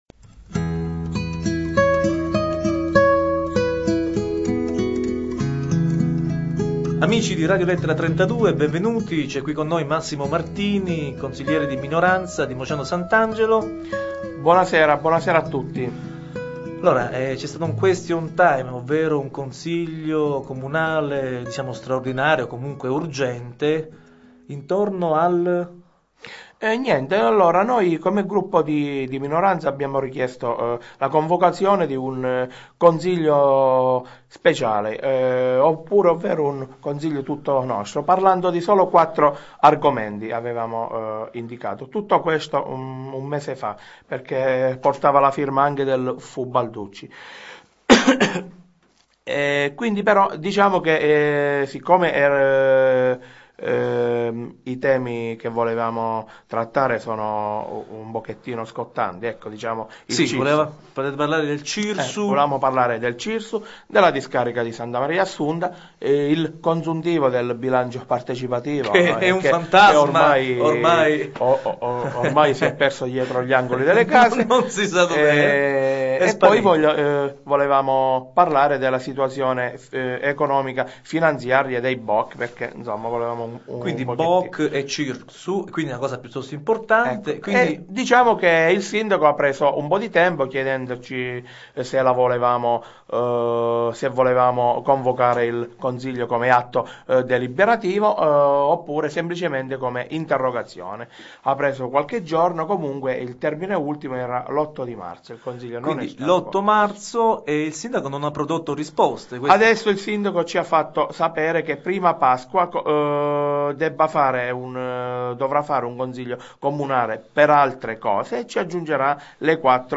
Abbiamo intervistato il consigliere Massimo Martini in merito.